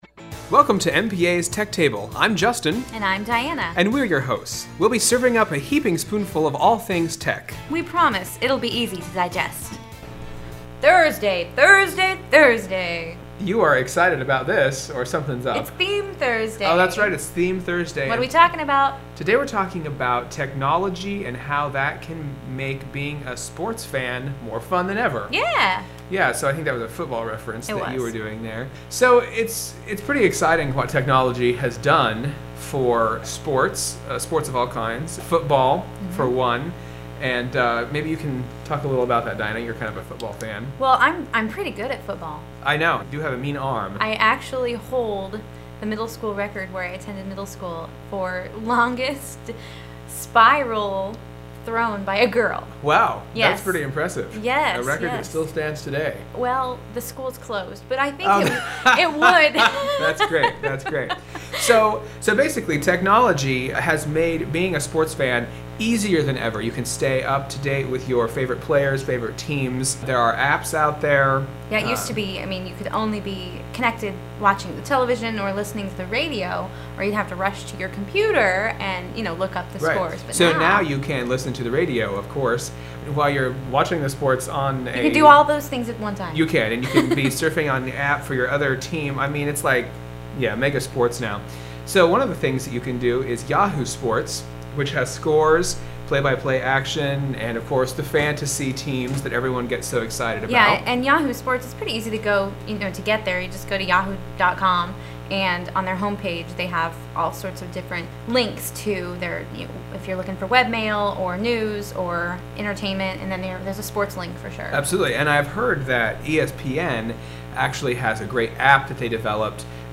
Tech Table Radio Show